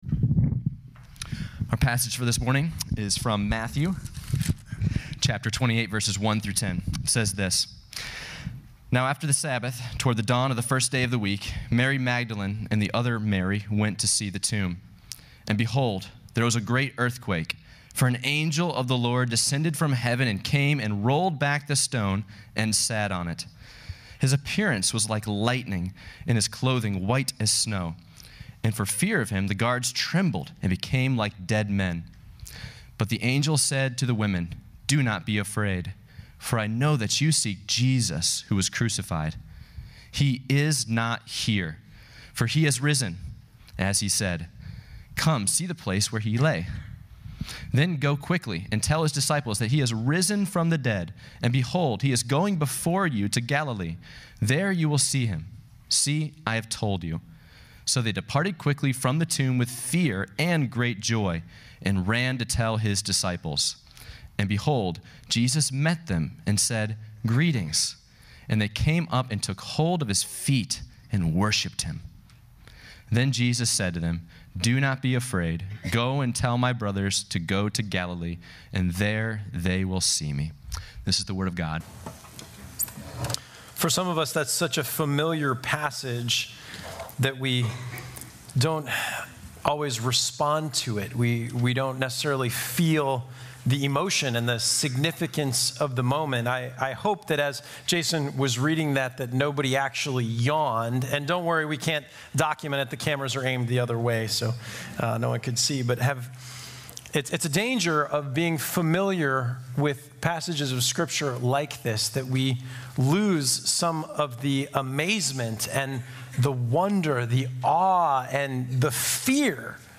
Easter-message-2023.mp3